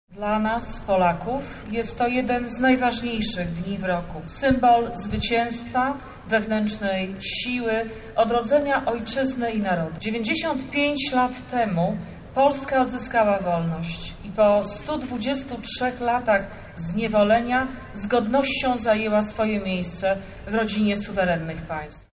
Dziś na Placu Litewskim odbyły się obchody odzyskania Niepodległości.
O tym jak ważne jest to święto mówiła Wojewoda Lubelska Jolanta Szołno – Koguc.